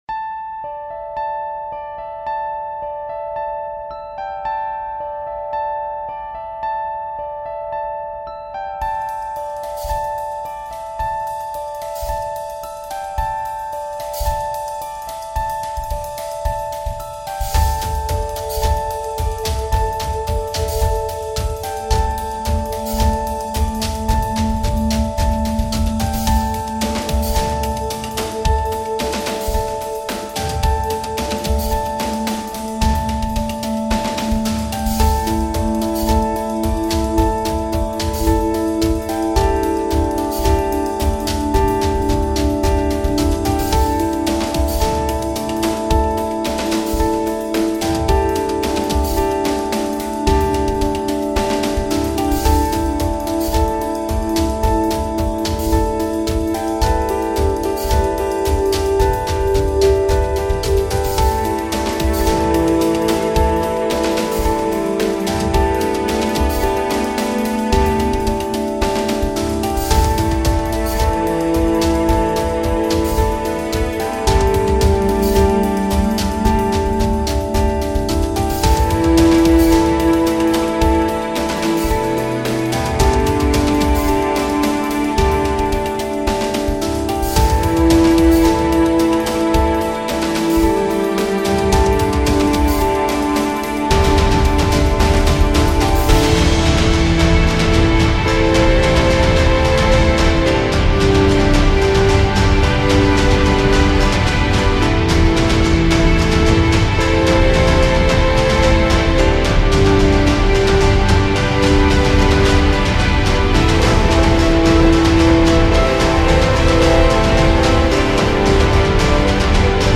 I wrote it to be rather rich with emotion.